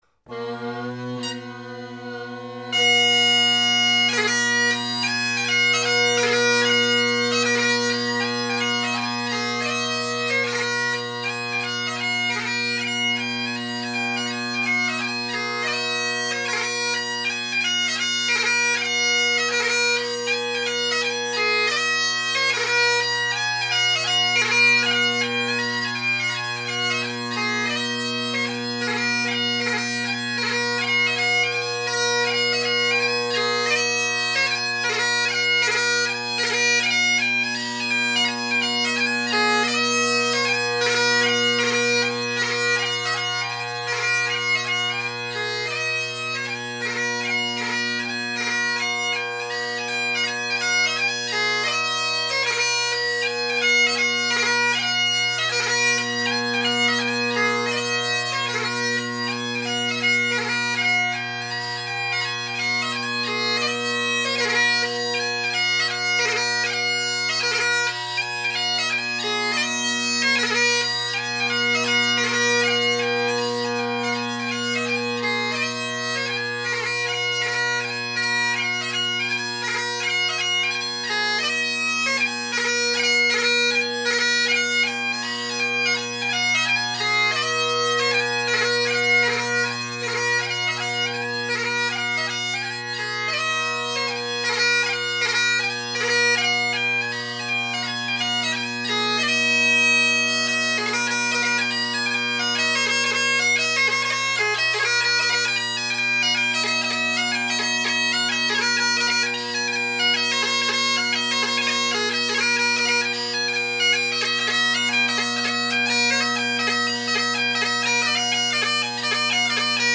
Great Highland Bagpipe Solo, Reviews